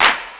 Southside Clapz (4).wav